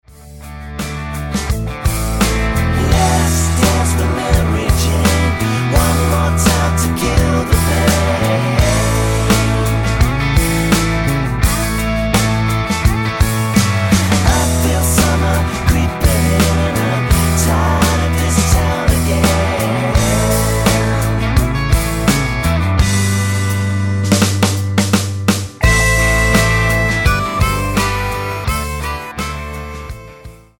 Tonart:Am mit Chor